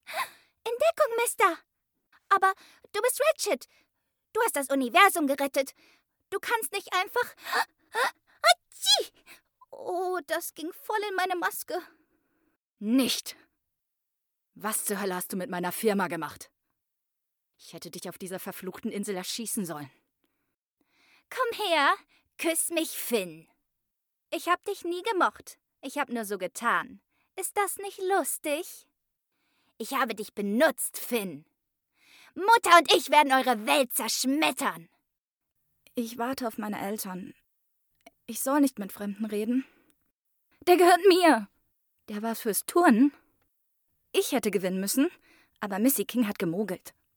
Female
IVR_ELEKTROWERK REGENSBURG.mp3
Microphone: Neumann TLM103, Rode NT1 A